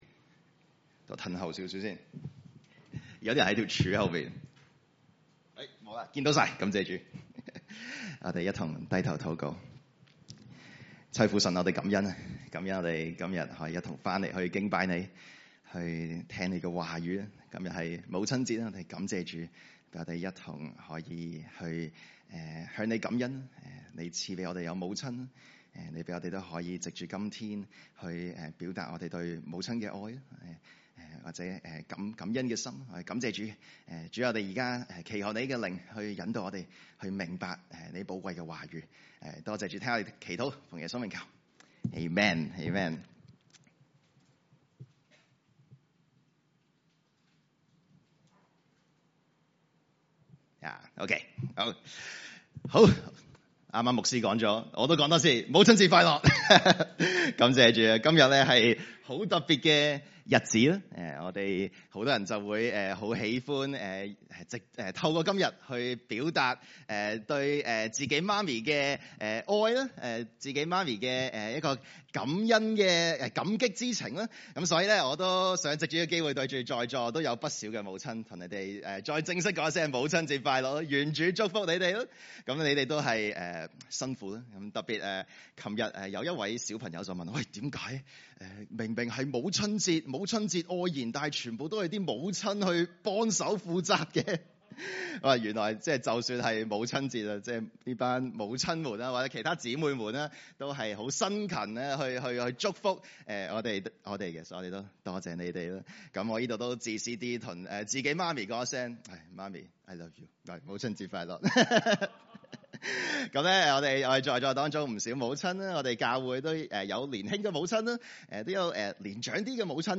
主日崇拜證道系列
來自講道系列 "解經式講道"